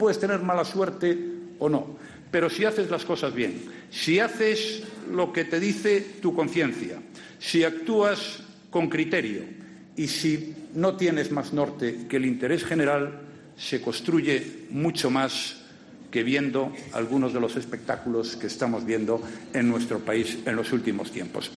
Rajoy ha entrado así en la polémica que se vive estos días -en los que el Gobierno se plantea garantizar en Cataluña la elección del castellano como lengua vehicular- aunque sin hablar específicamente del catalán o de otra lengua cooficial, durante su intervención en una convención sobre pymes y autónomos organizada por el PP en Elche.